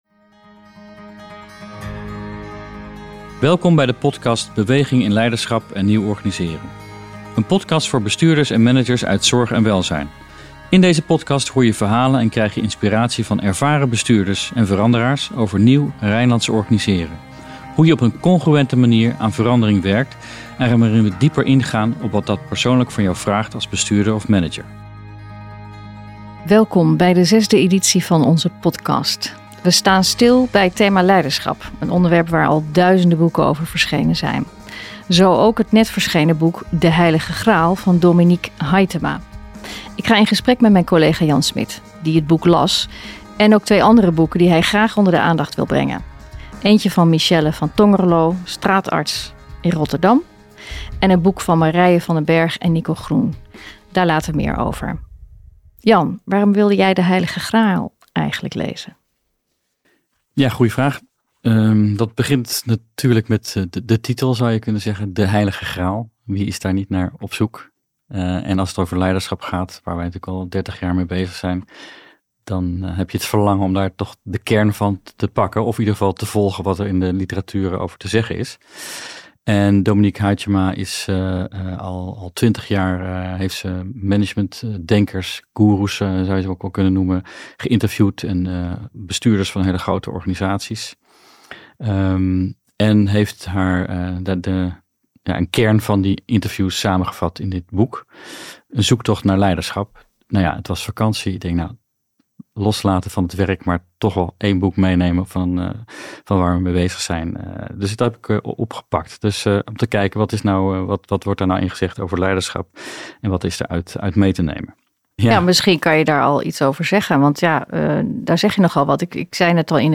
Beweging in Leiderschap en Nieuw Organiseren Welzijnswerk op zijn Rijnlands - een interview